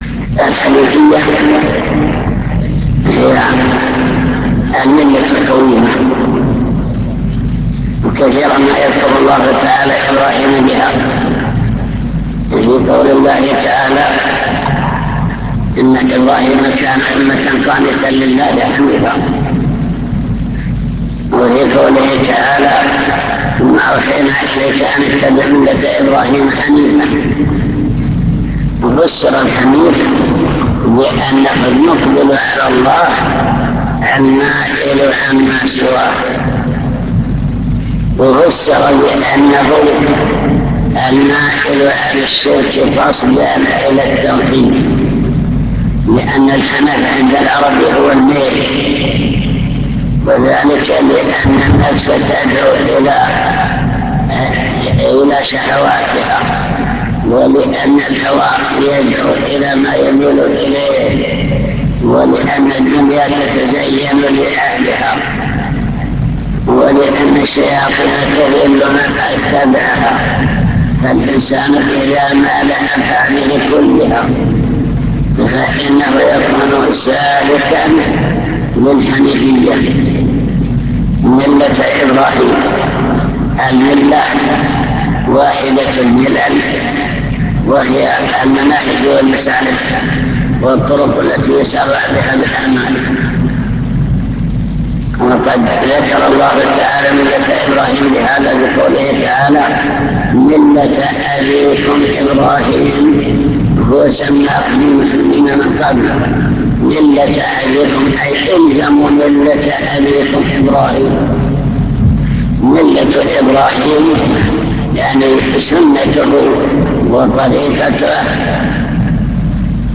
المكتبة الصوتية  تسجيلات - كتب  شرح القواعد الأربعة مقدمة الكتاب